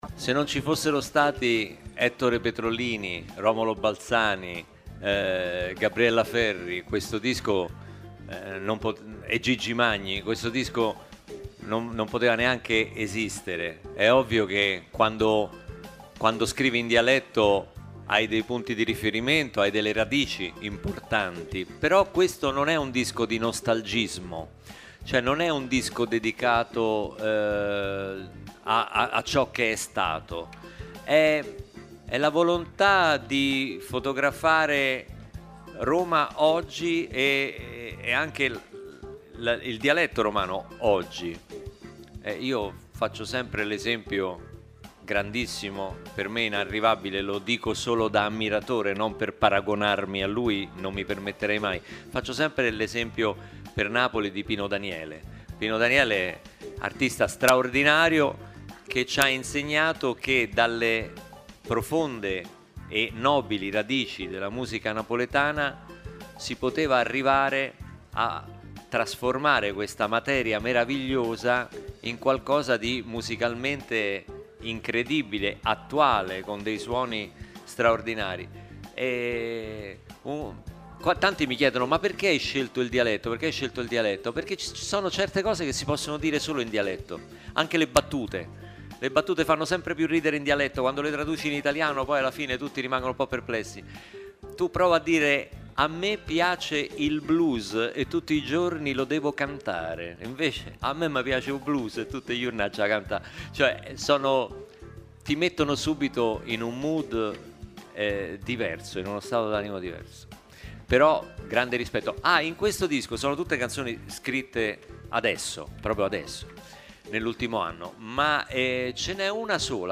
In sala stampa arriva Luca Barbarossa.